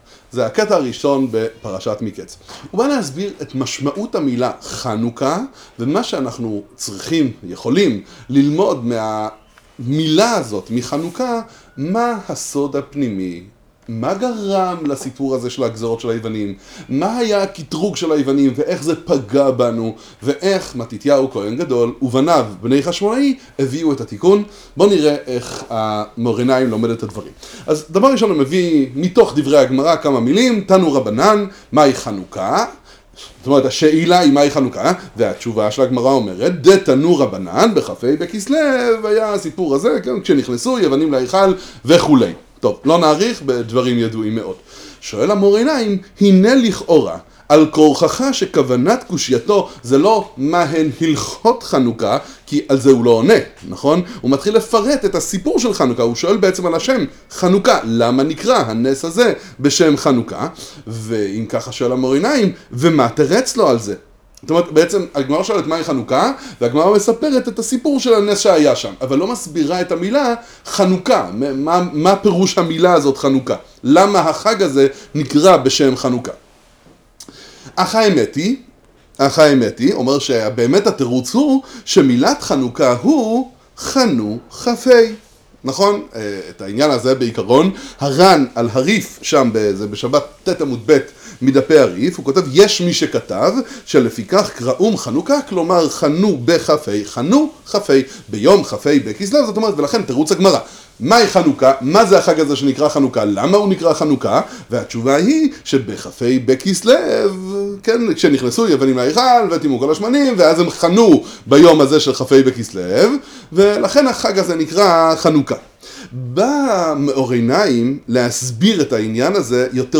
שיעור עמוק בחסידות להבנת ענין חנוכה